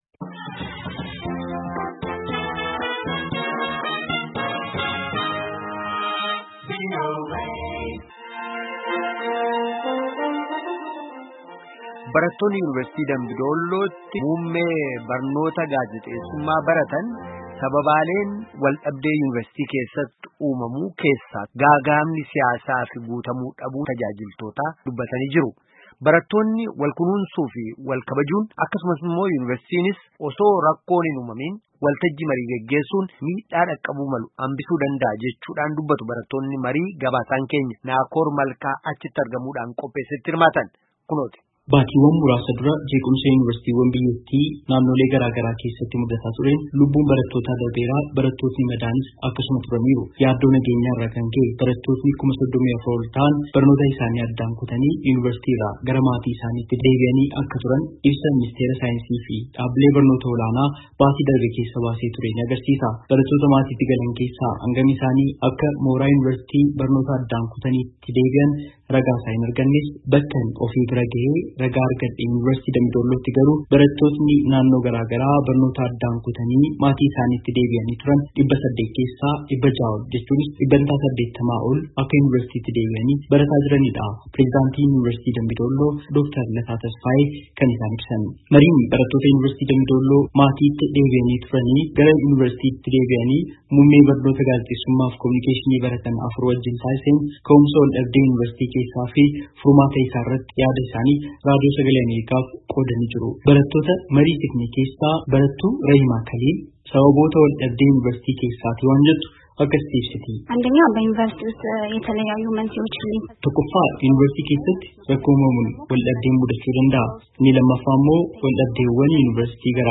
Yunivarsiitii Dambii Doollootti barattoonni Muummee Barnoota Gaazexessummaa hagi VOA waliin marii geggeessaniin sababaaleen wal-dhibdee Yunivarsitii isaanii keessatti mul’atee lama gaaga’ama siyaasaa fi guutamuu dhabuun tajaajilootaa tahuu dubbatan.